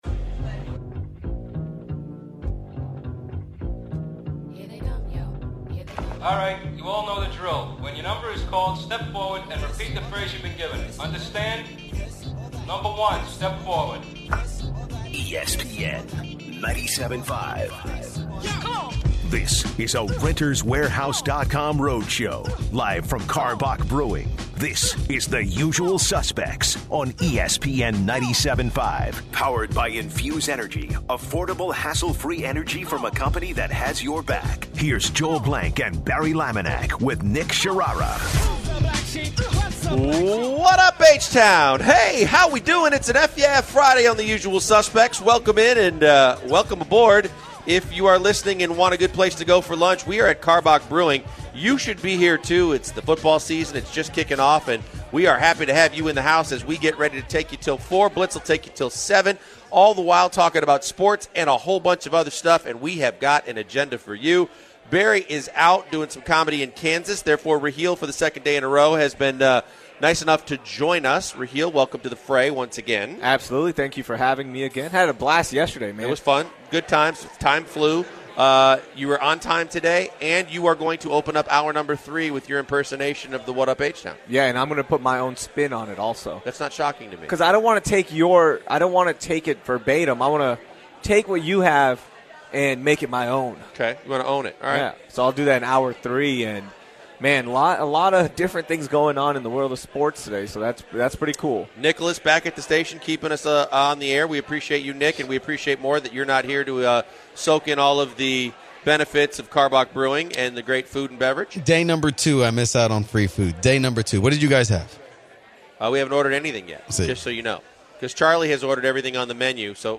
Live from Karbach Brewery